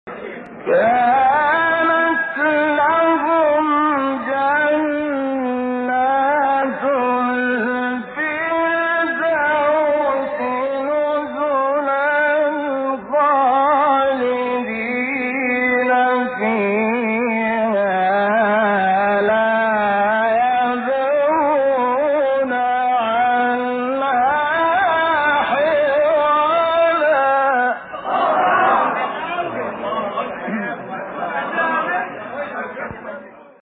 شبکه اجتماعی: مقاطع صوتی از تلاوت قاریان برجسته مصری ارائه می‌شود.